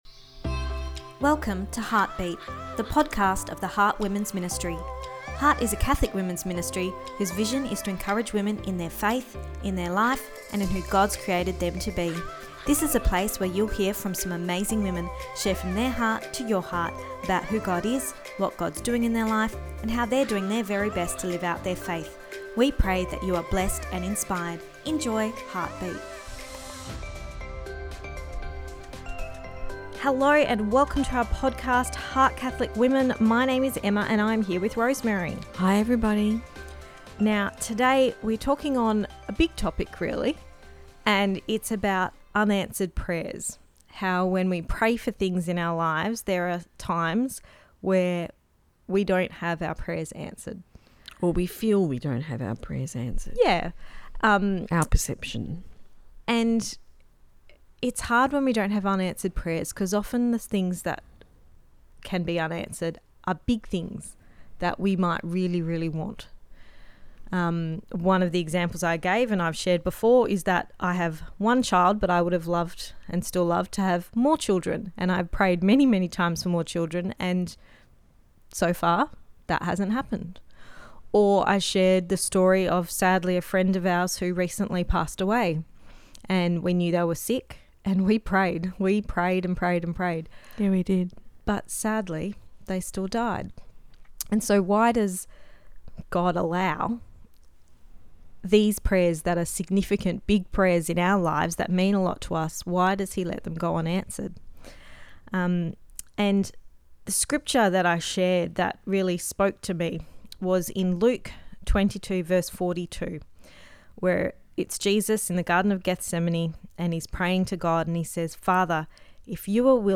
episode-98-do-you-have-unanswered-prayers-part-2-our-chat.mp3